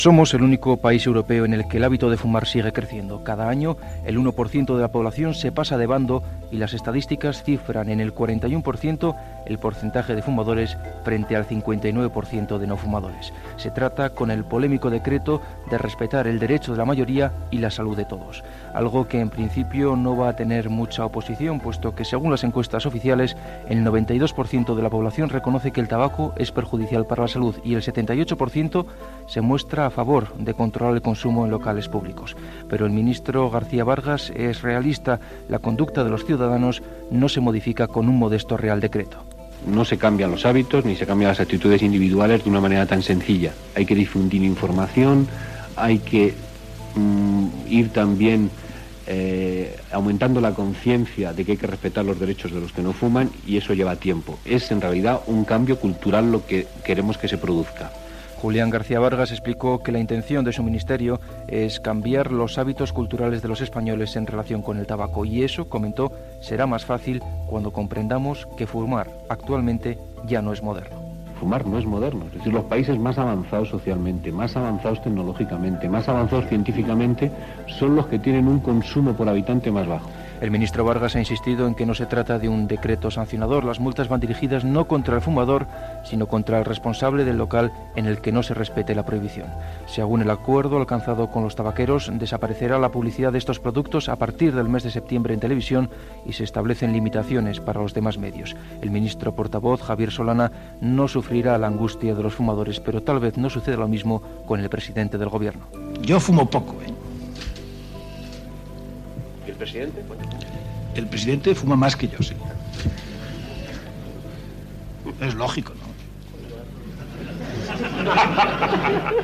Enquesta al carrer de Madrid sobre l'opinió de la ciutadania sobre la Llei antitabac.
Informatiu